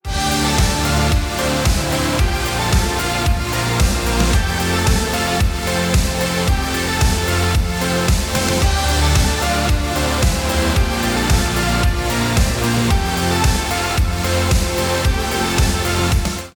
Хард бас